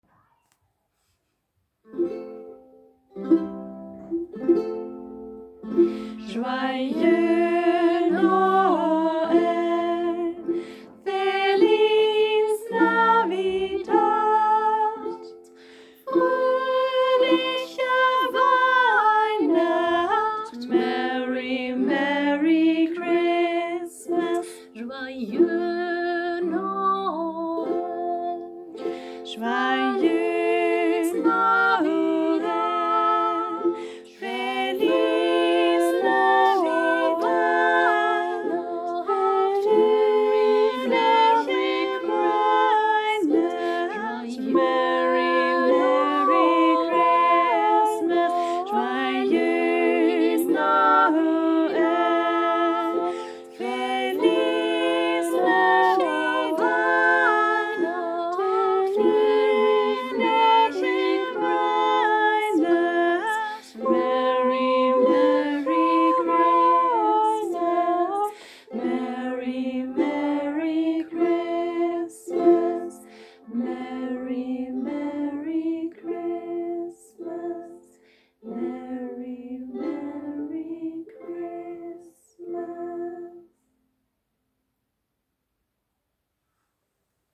Joyeux Noël, Feliz Navidad, Fröhliche Weihnacht und Merry Christmas verbinden sich zu einem eingängigen,  stimmungsvollen Kanon.
Weihnachtsgrüße aus aller Welt - zum Hineinhören (hier in F-Dur wie im Liedblatt, begleitet mit nur 2 Akkorden, die auf der Ukulele einfach zu greifen sind - nur Mut zum Nachmachen!)
Weihnachtskanon_F-Dur_BLKM2026.m4a